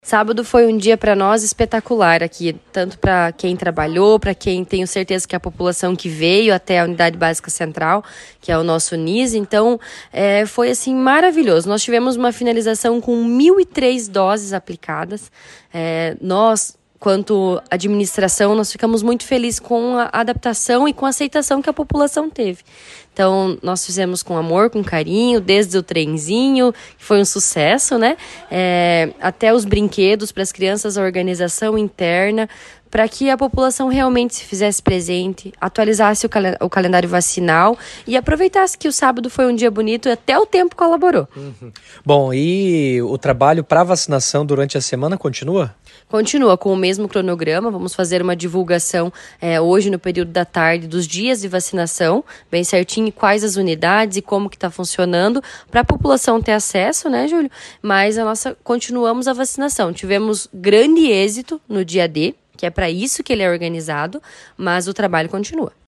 A secretária de saúde do município, Jailine Bortolomedi, avalia o trabalho realizado e reforça o pedido para que as pessoas que não se vacinaram procurem as unidades de saúde.